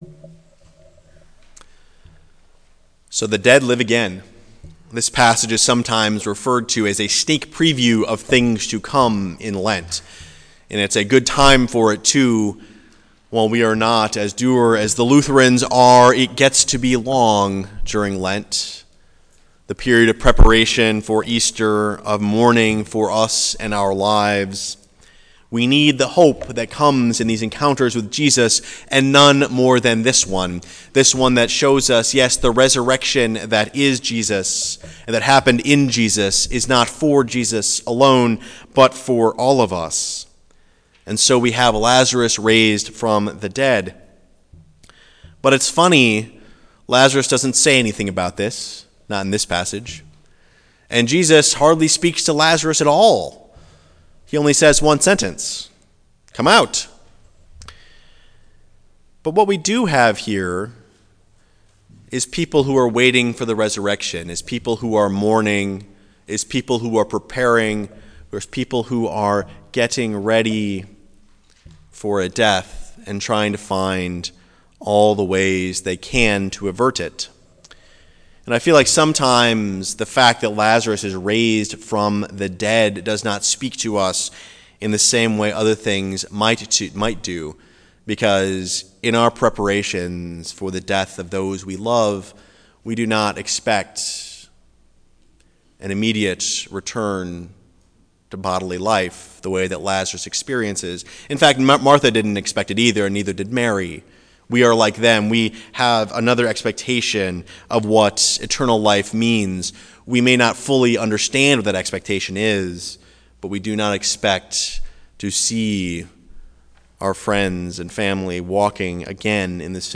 Preached in Ankeny UCC